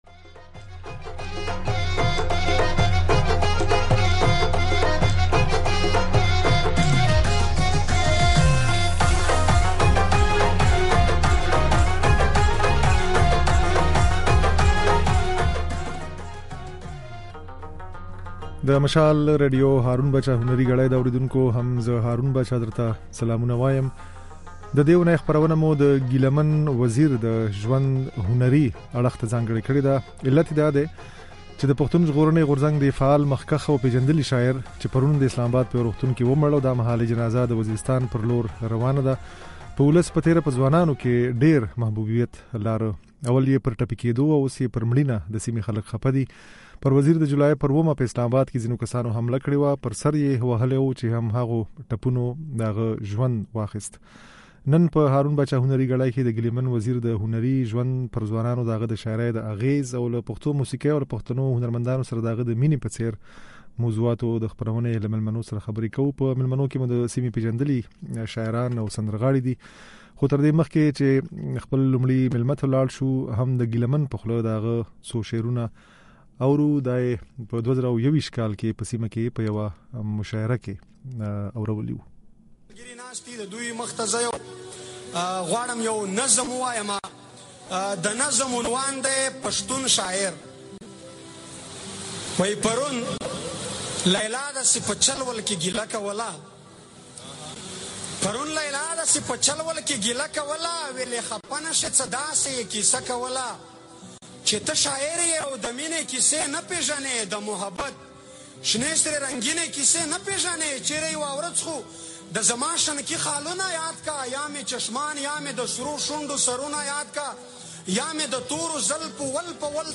د دې اوونۍ "هارون باچا هنري ګړۍ" خپرونه مو د ګيله من وزير د ژوند هنري اړخ ته ځانګړې کړې ده. هغه د پښتون ژغورنې غورځنګ فعال مخکښ او پېژندلی شاعر وو. په خپرونه کې د ګيله من وزير د شعر، له موسيقۍ سره يې د مينې او د ولسي محبوبيت د لاملونو په اړه د سيمې د پېژندليو سندرغاړو او شاعرانو خبرې اورېدای شئ.